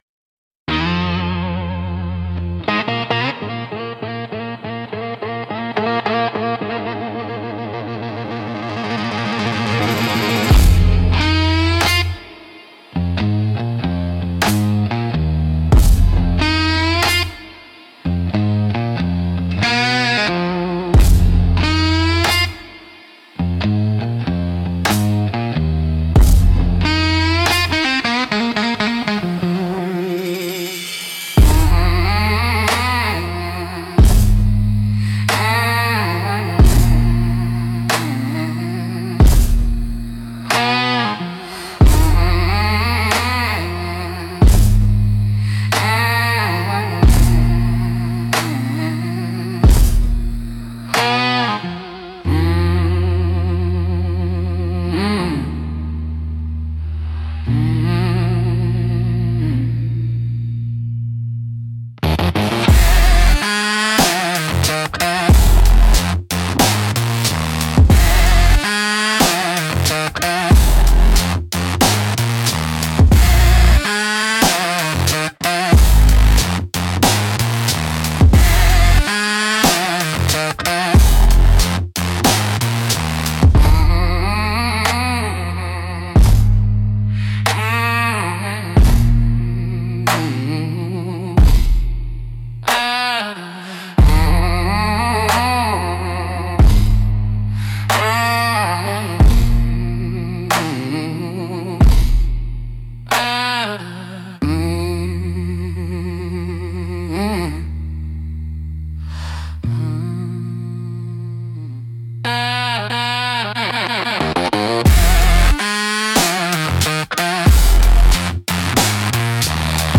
Instrumental - Crows in the Circuit.